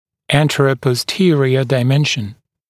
[ˌæntərəpɔs’tɪərɪə daɪ’menʃn][ˌэнтэрэпос’тиэриэ дай’мэншн]сагиттальная плоскость; сагиттальное измерение